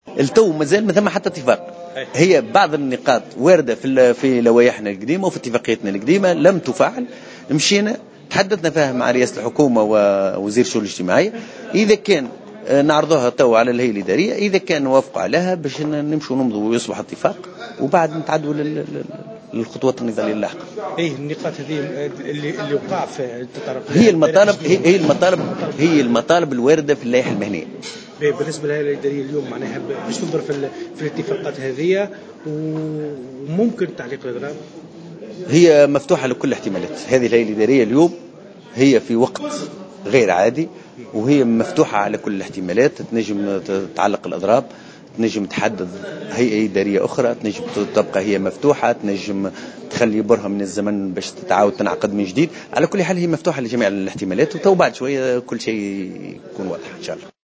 وأشار في تصريح لمراسل الجوهرة اف ام، إلى إمكانية تعليق الإضراب، المقرر يومي 8 و9 مارس 2017، متابعا "جميع الإمكانيات واردة". ومن أهم المطالب التي تنادي بها النقابة العامة للتعليم الأساسي، التقاعد المبكر والمنح الجامعية والترقيات الاستثنائية.